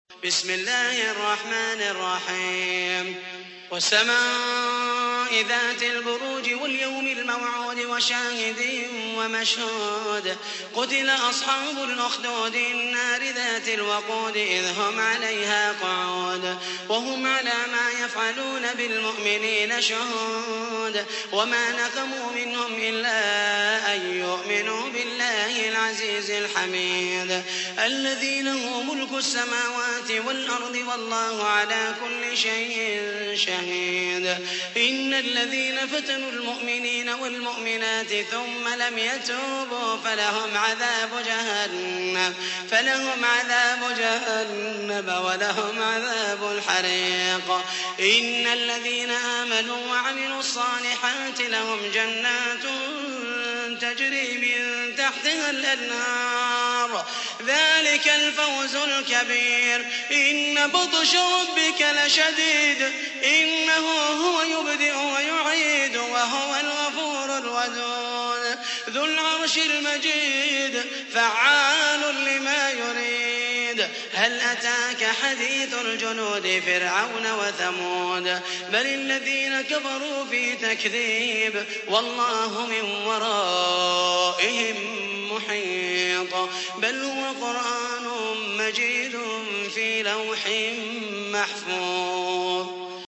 تحميل : 85. سورة البروج / القارئ محمد المحيسني / القرآن الكريم / موقع يا حسين